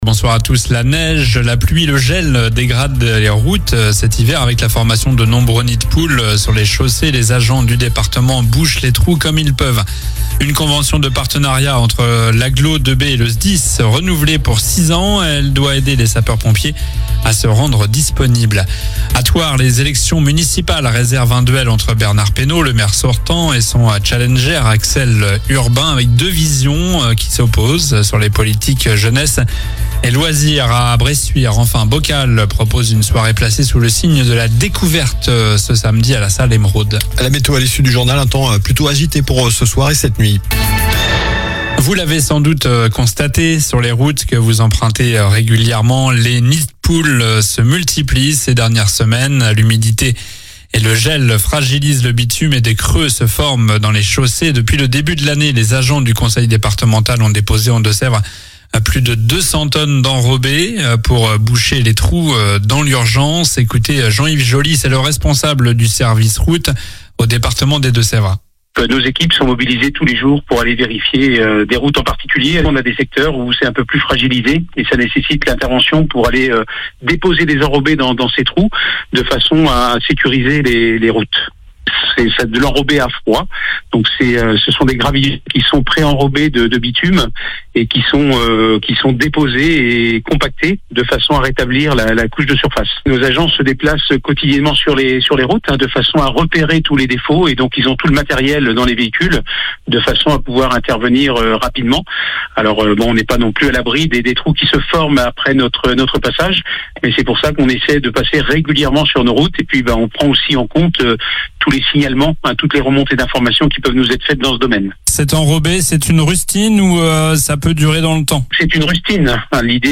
Journal du mercredi 18 février (soir)